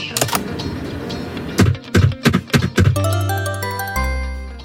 Download Buffalo Slot Machine sound effect for free.
Buffalo Slot Machine